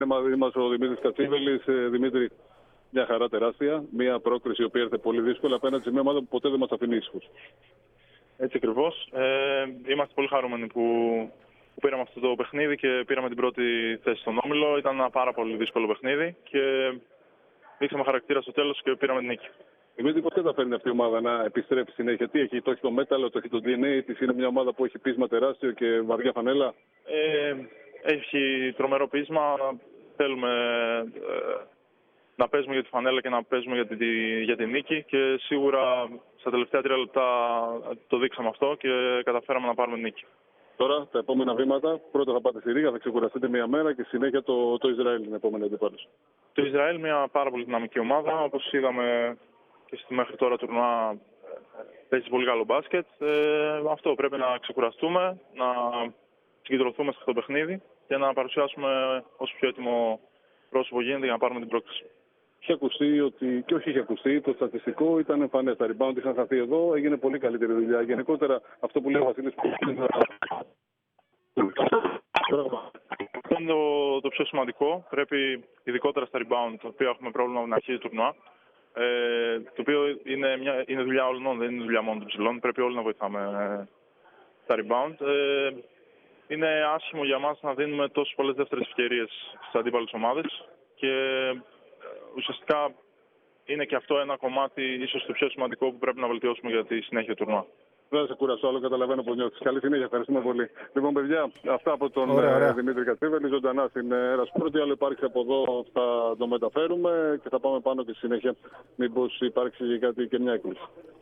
Ο διεθνής γκαρντ μίλησε μετά το τέλος της αναμέτρησης με την Ισπανία, όπου η Εθνική ομάδα πήρε την πρώτη θέση στον όμιλο, τονίζοντας πως όλο το σύνολο έδειξε χαρακτήρα στο τέλος.